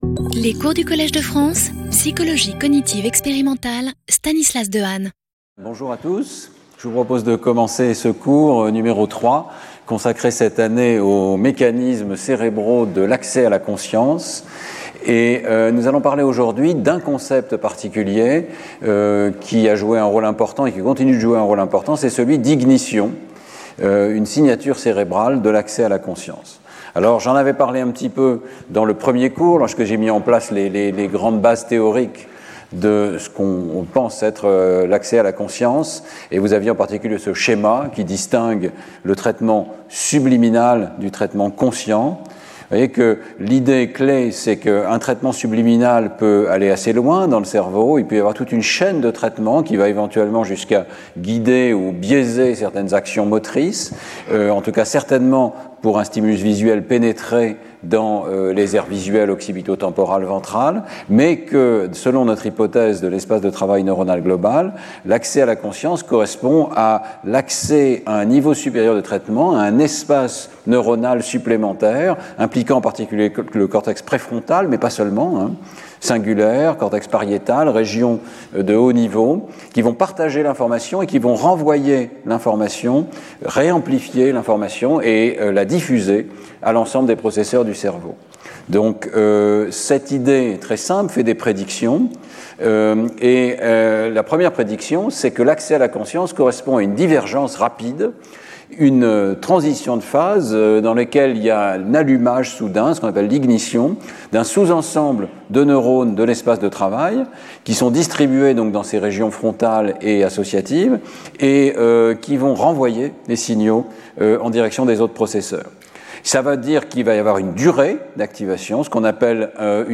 Intervenant(s) Stanislas Dehaene Professeur du Collège de France
Cours